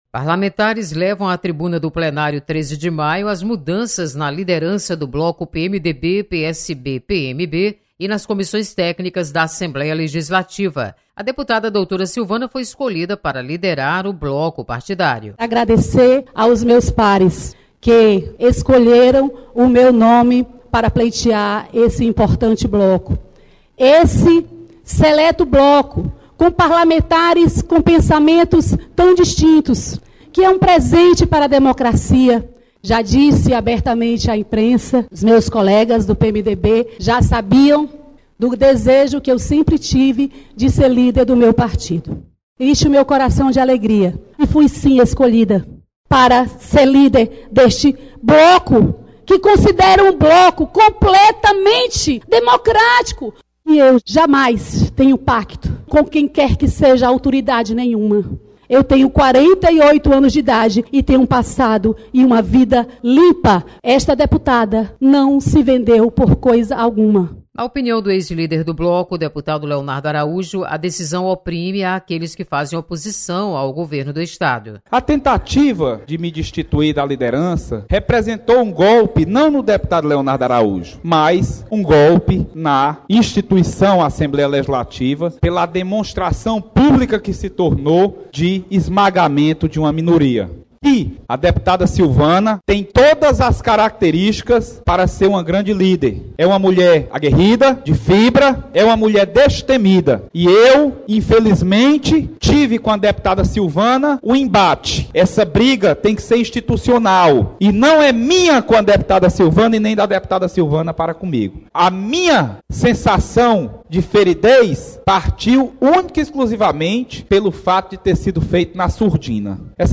Mudanças no bloco PMDB-PSD-PMB e nas comissões técnicas. Repórter